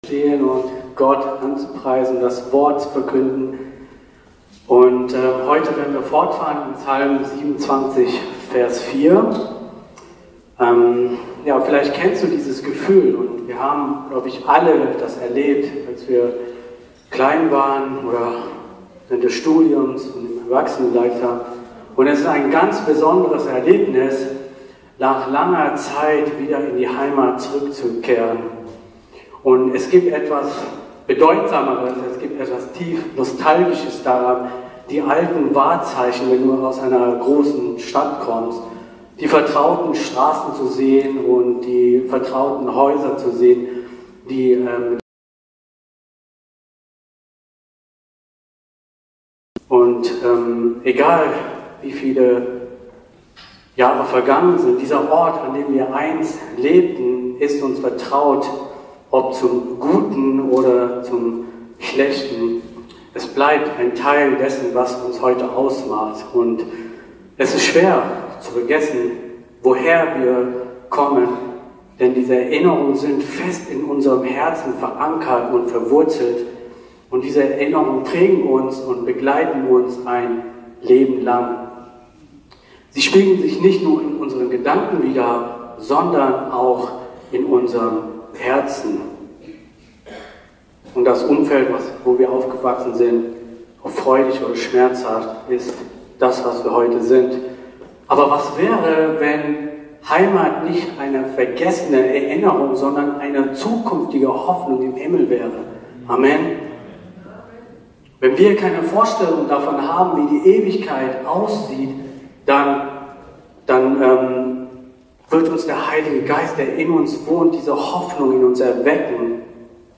Predigtnotizen: Wohnen in Gottes Haus, Psalm 27,4: